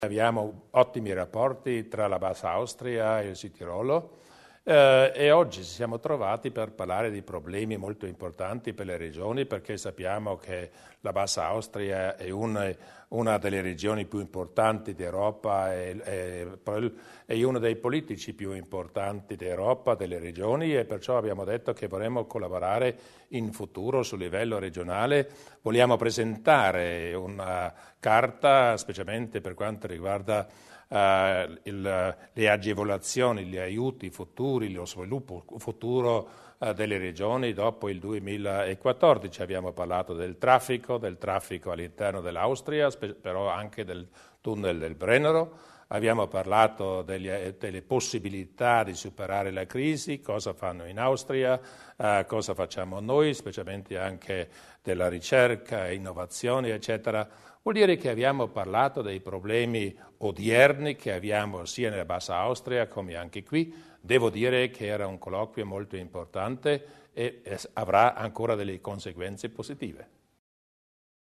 Landeshauptmann Pröll über das Treffen mit Landeshauptmann Durnwalder